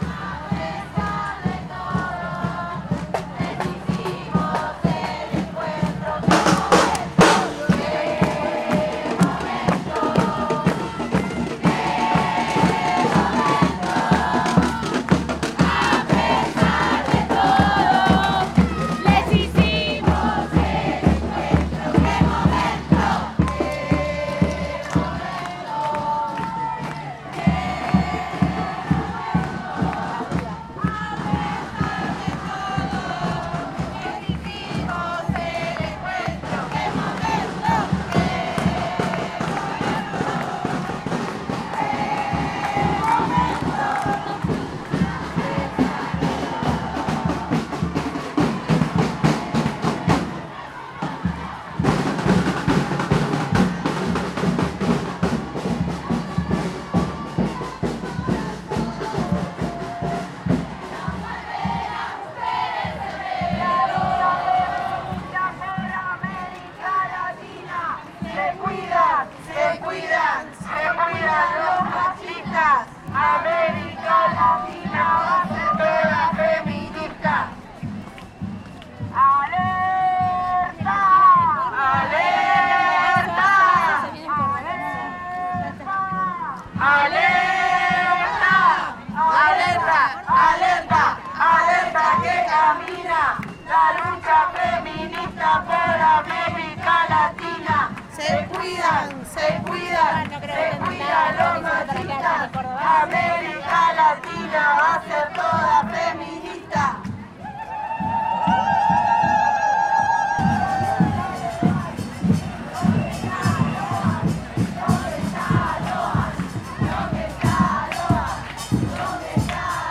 Marcha Ciudad de Corrientes, Corrientes 23 de Noviembre 2025
oa-38-encuentro-epmlttnb-ciudad-de-corrientes-corrientes.mp3